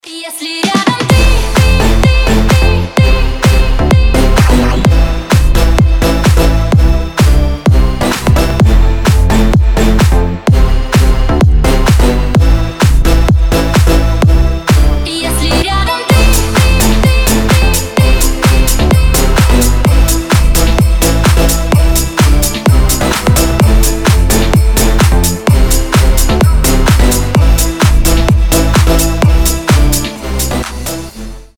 позитивные
мелодичные
зажигательные
Club House